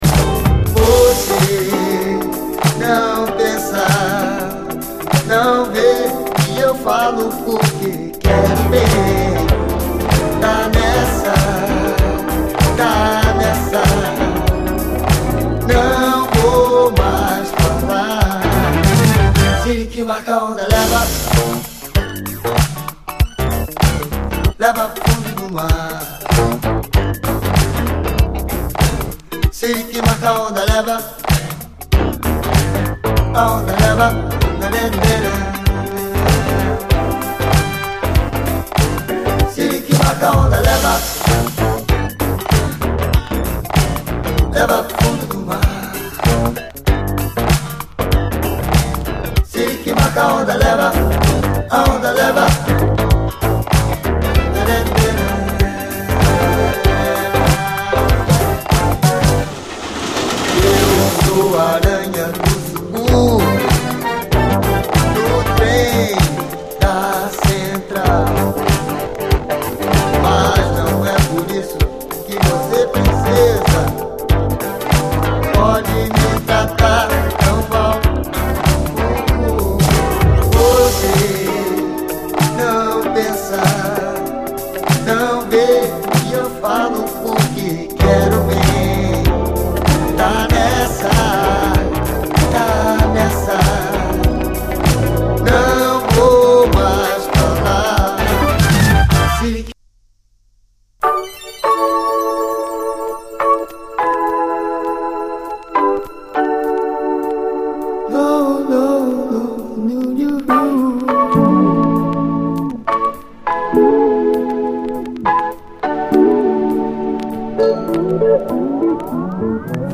透き通るようなハーモニーが美しい、80’S男女ジャズ・コーラス〜フュージョン・グループ！
スピリチュアルに儚く響くシンセ・サウンド＆コーラスが陶酔させます。マリンバ＆スティールパン入りのメロウ・フュージョン